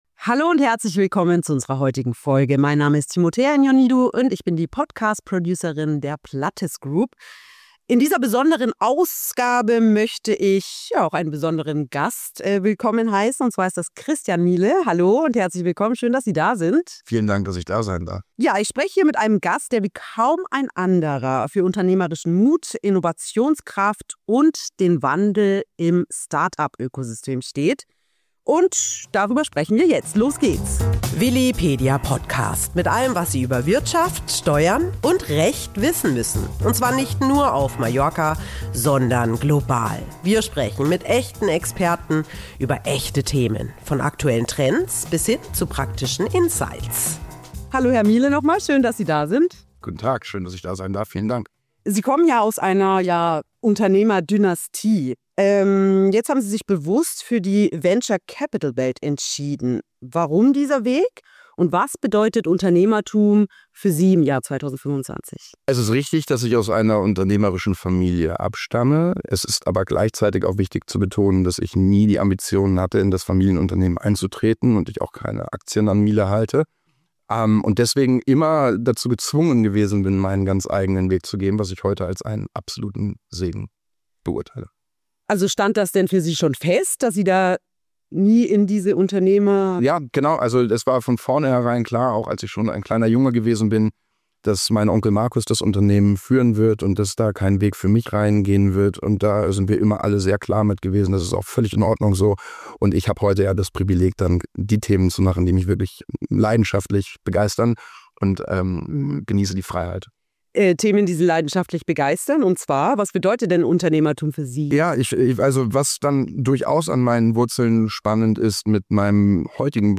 Unternehmertum neu gedacht – Christian Miele im Gespräch über Startups, Kapital und Innovation